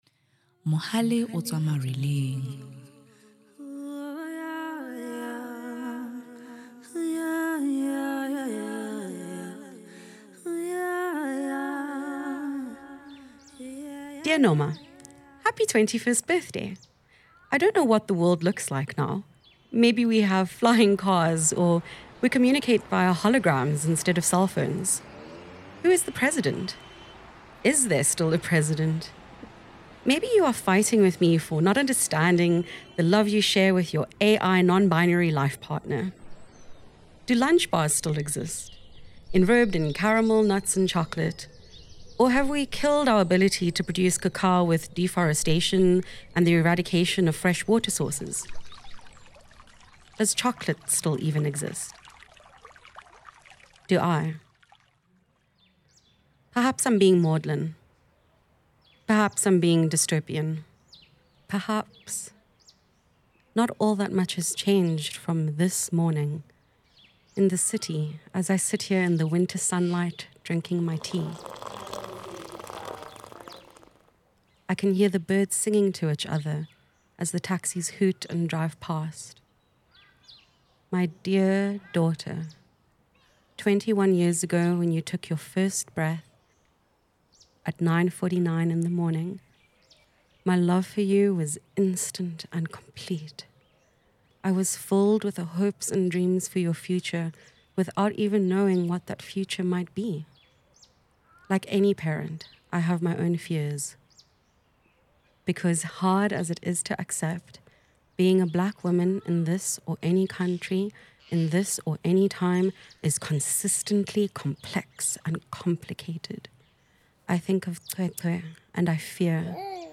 For many in the group, Hold These Letters Dear was their first experience with narrative podcasting, a format that requires structure, emotional pacing, and strong storytelling arcs. With fresh skills in hand, they planned the episode’s sonic landscape from the start, including field recordings to add texture and authenticity.
Together, they developed the script, sound design, and thematic cohesion, transforming their collective vision into a compelling audio experience.